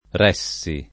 vai all'elenco alfabetico delle voci ingrandisci il carattere 100% rimpicciolisci il carattere stampa invia tramite posta elettronica codividi su Facebook reggere [ r $JJ ere ] v.; reggo [ r $gg o ], ‑gi — pass. rem. ressi [ r $SS i ]; part. pass. retto [ r $ tto ]